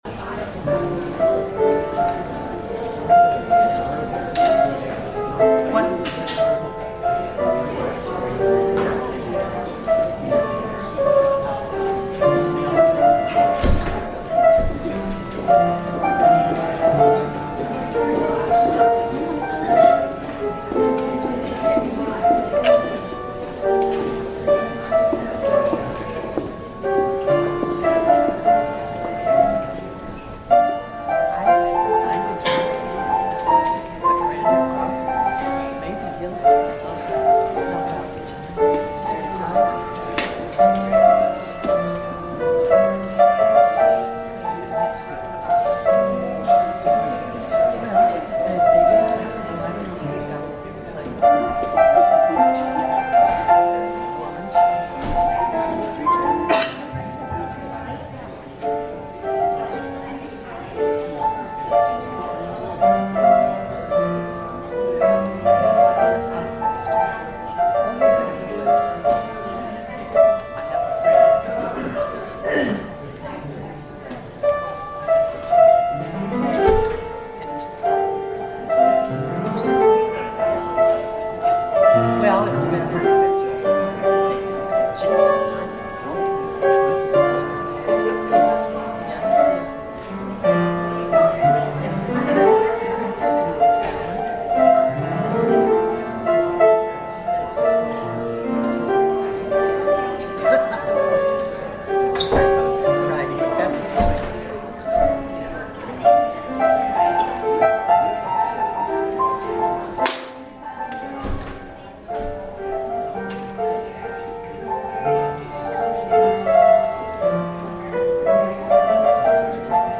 Audio recording of the 10am hybrid/streamed service (in the Parish Hall)
We have been worshiping in the Parish Hall, which doesn’t have the same recording capabilities.